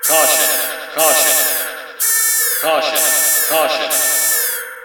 hullbreech.ogg